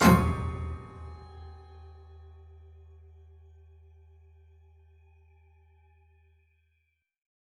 A series of dense, sharply articulated but rather "hollow" chords, sometimes taking the form of guillotine-like "slices," as in